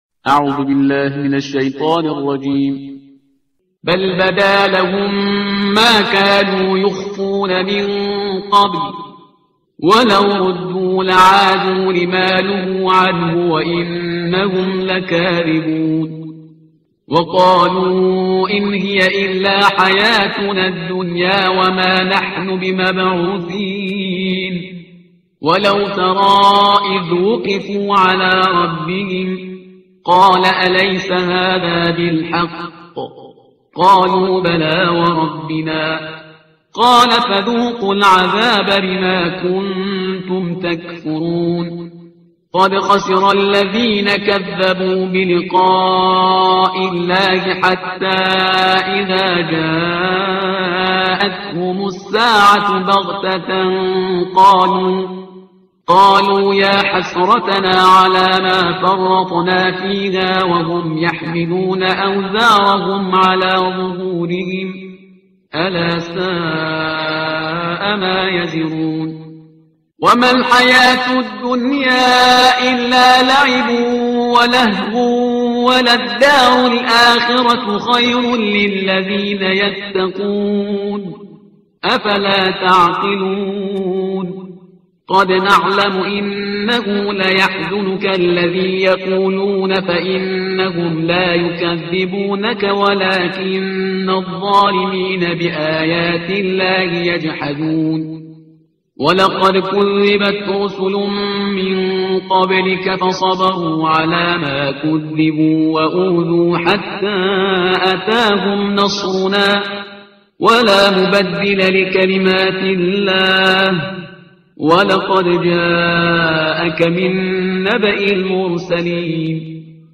ترتیل صفحه 131 قرآن – جزء هفتم